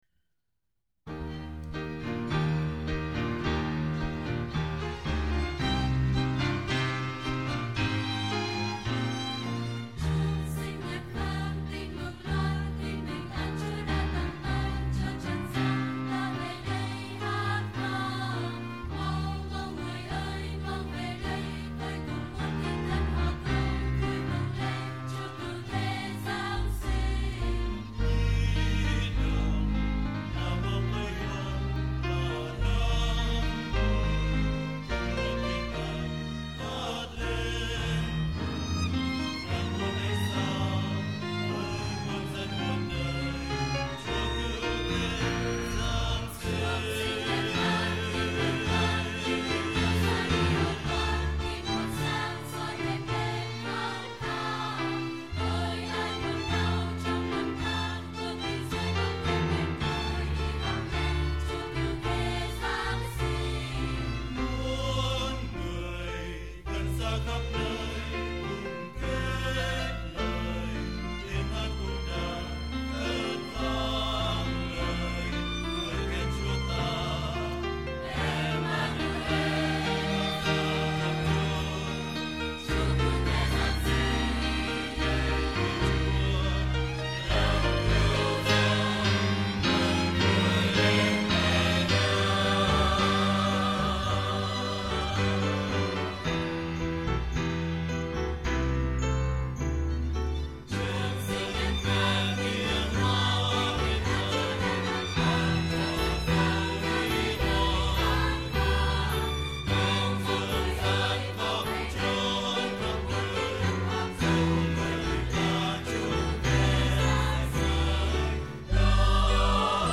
Gửi đến quý anh chị bài hát Christmastime, trích trong chương trình Nhạc Hội Giáng Sinh 2009 của ca đoàn Việt Linh, St. Columban Church. Bài này hát ca đoàn hát Live với dàn nhạc Orchestra Bolsa Essemble và được recording trực tiếp without edit.